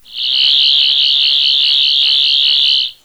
sonicscrewdriver_11thDoc.wav